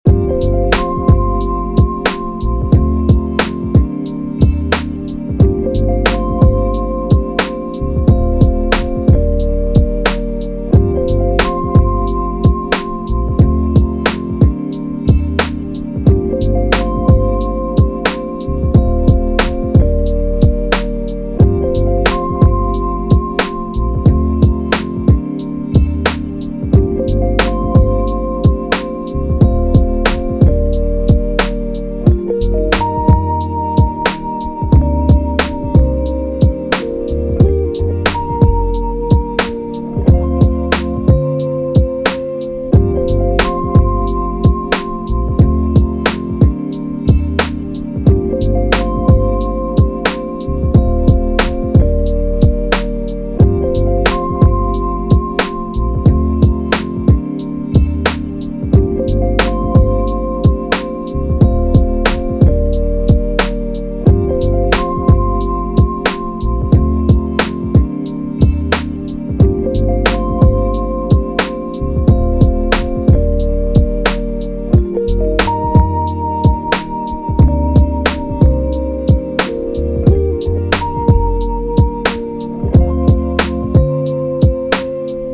Soul, 90s, R&B, Hip Hop
G Min